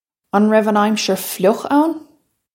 Pronunciation for how to say
Un rev un ime-sher fl-yukh own?
This is an approximate phonetic pronunciation of the phrase.